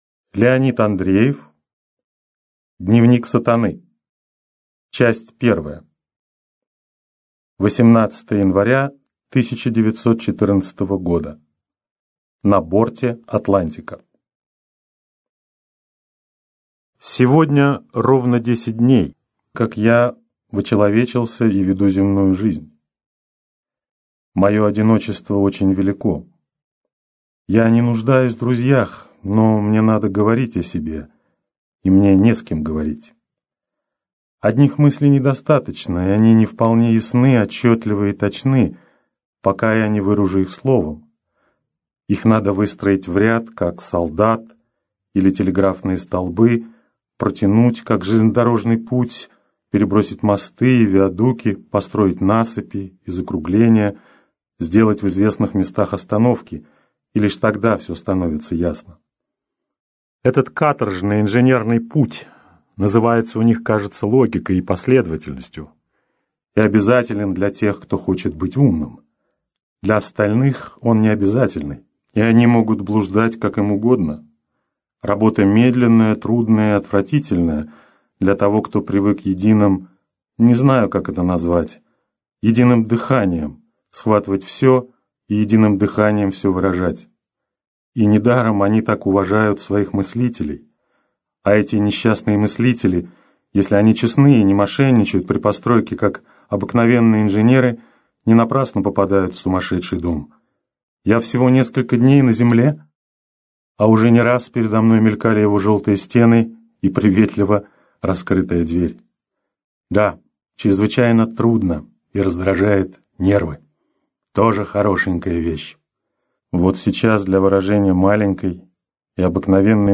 Аудиокнига Дневник Сатаны | Библиотека аудиокниг
Прослушать и бесплатно скачать фрагмент аудиокниги